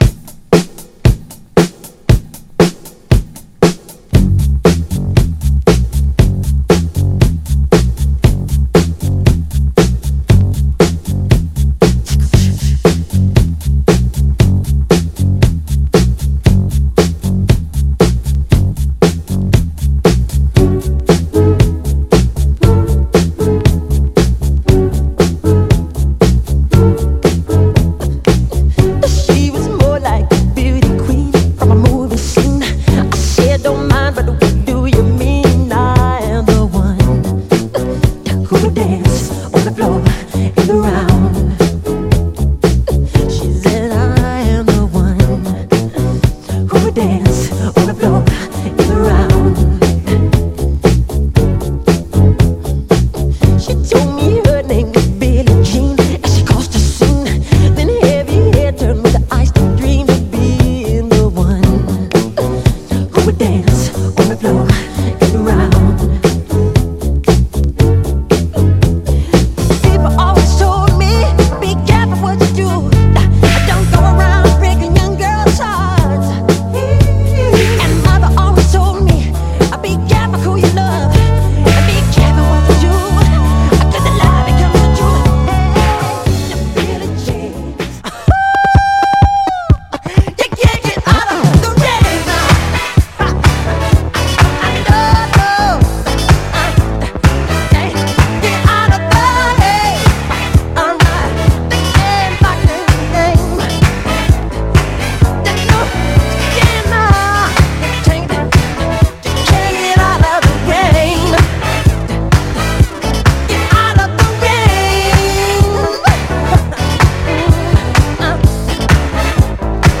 爆発力のあるブギー・ファンク
盤はいくつか細かいスレ箇所ありますが、グロスがありプレイ良好です。
※試聴音源は実際にお送りする商品から録音したものです※